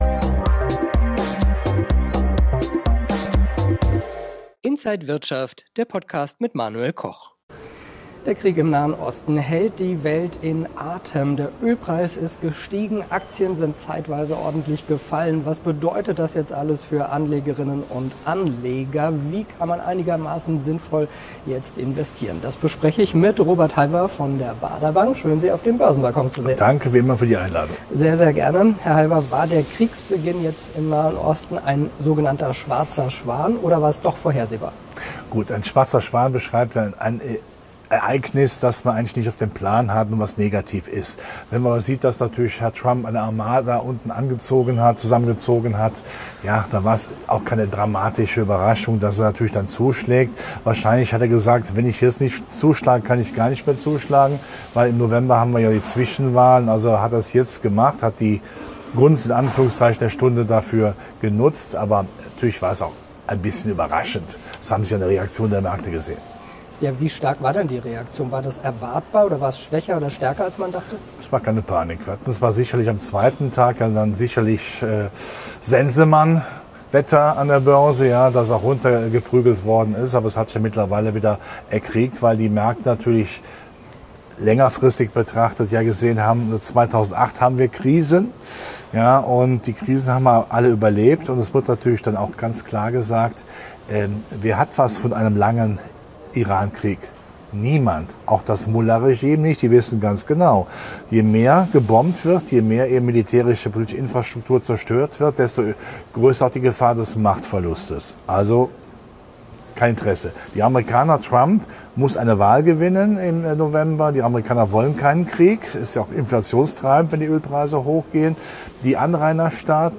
Interview wurde am 5. März 2026 aufgezeichnet.